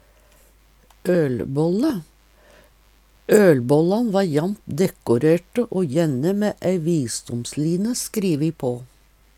ølbålle - Numedalsmål (en-US)